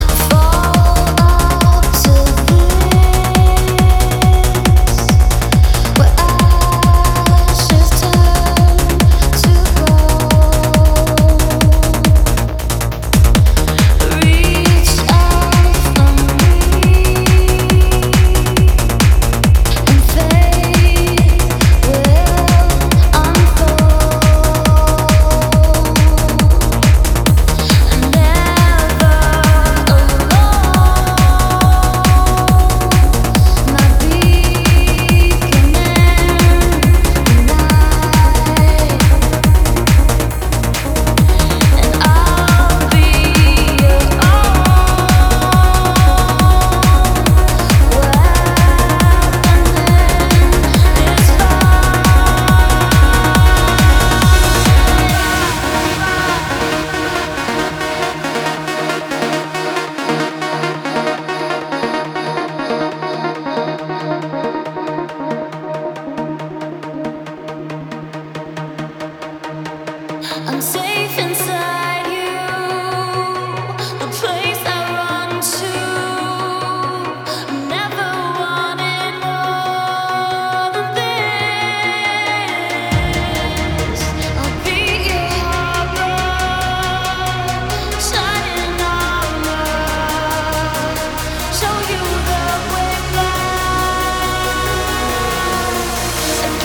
club
Trance
vocal